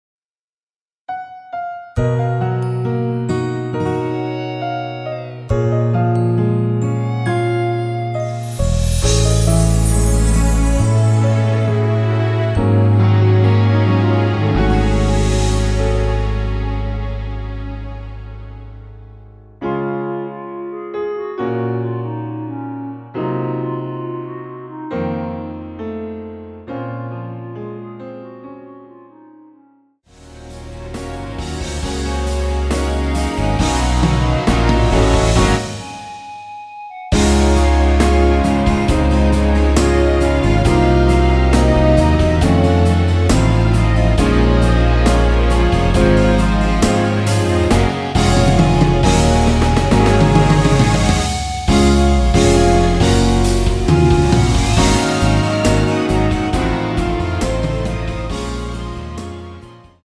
원키에서(-2)내린 멜로디 포함된 MR입니다.
Db
앞부분30초, 뒷부분30초씩 편집해서 올려 드리고 있습니다.